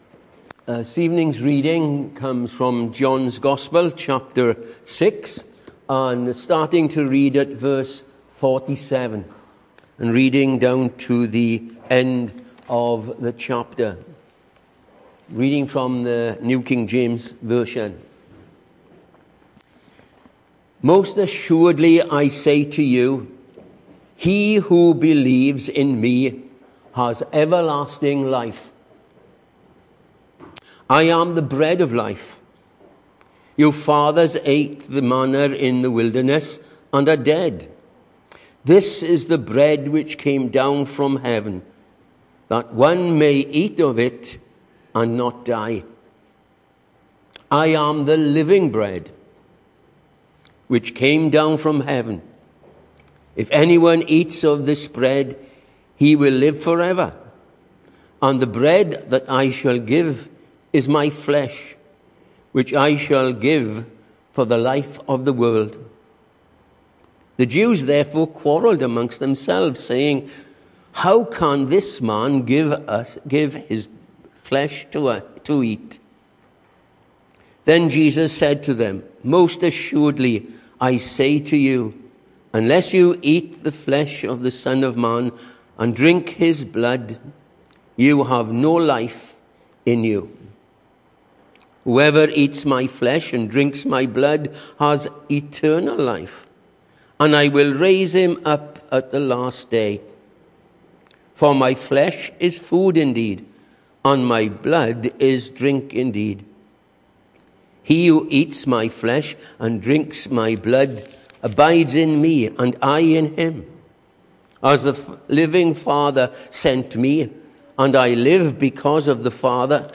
Hello and welcome to Bethel Evangelical Church in Gorseinon and thank you for checking out this weeks sermon recordings.
The 10th of August saw us hold our evening service from the building, with a livestream available via Facebook.